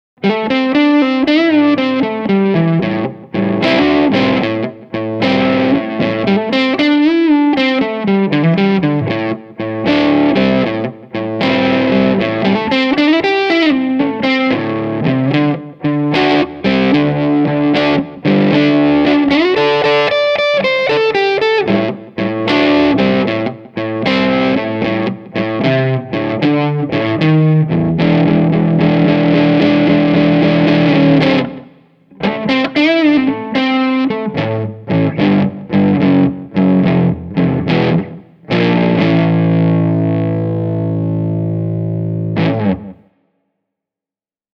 Reverb and echo have been added at the mixing stage.
Studio Custom – ch 2 – drive
hamer-studio-ch-2-crunch.mp3